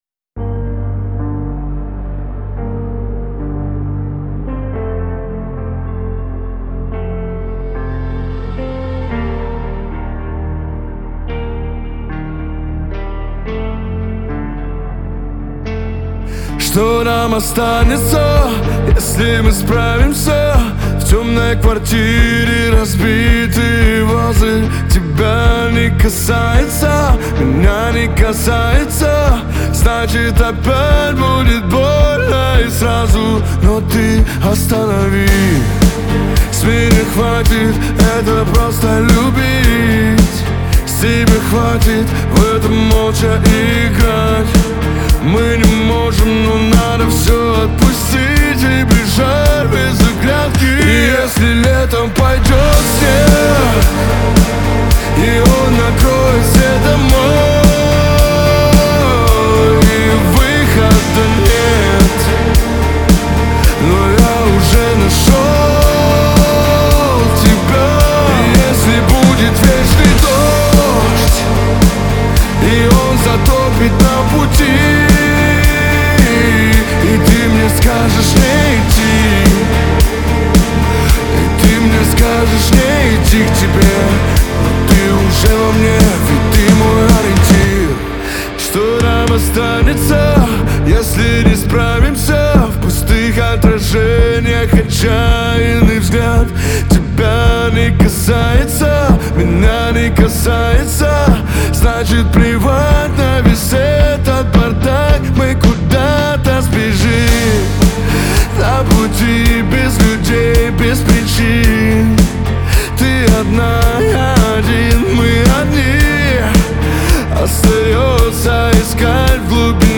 ХАУС-РЭП
эстрада